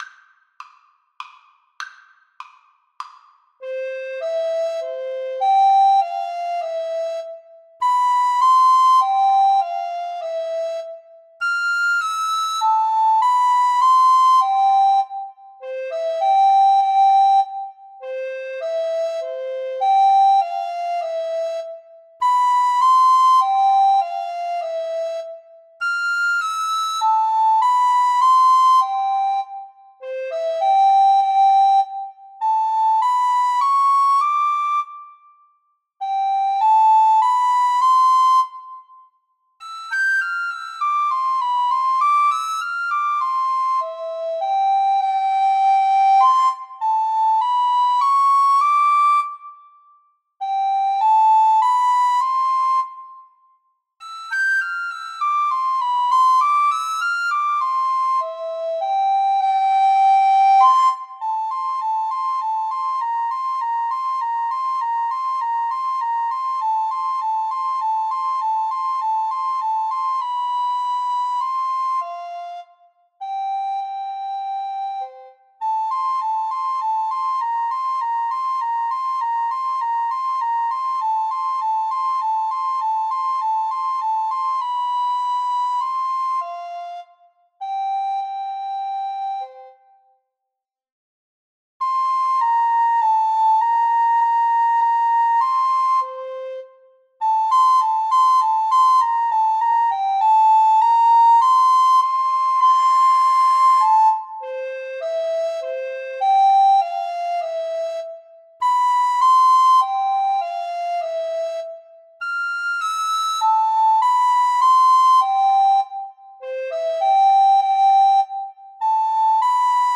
Descant Recorder 1Descant Recorder 2
3/4 (View more 3/4 Music)
Menuetto
Classical (View more Classical Recorder Duet Music)